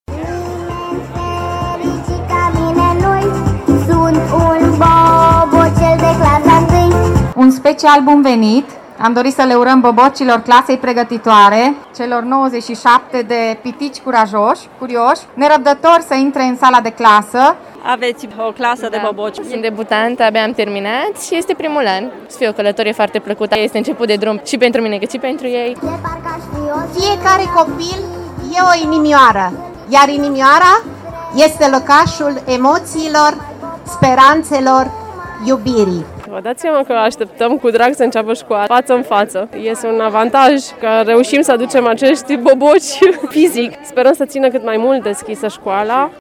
O astfel de activitate a adus astăzi zâmbete pe fețele tuturor celor prezenți la festivitățile organizate la Gimnaziul ”Tudor Vladimirescu” din Târgu-Mureș: